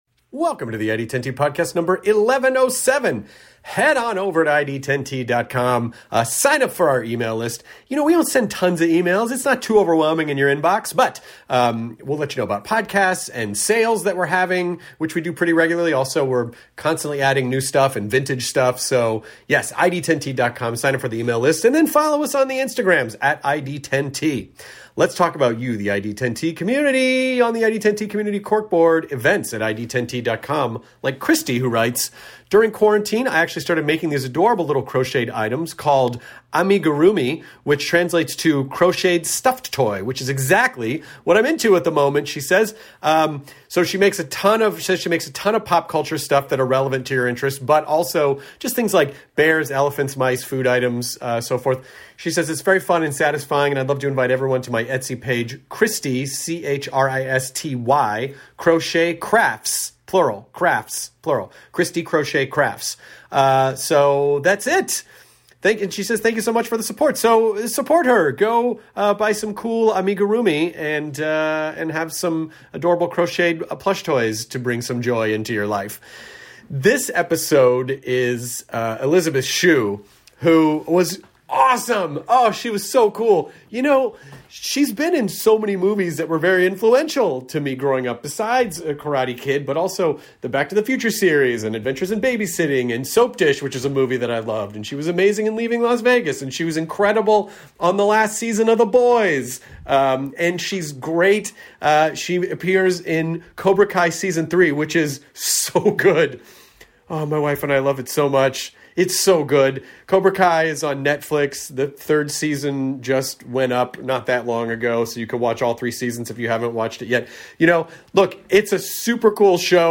Elisabeth Shue is a fantastic guest on the podcast!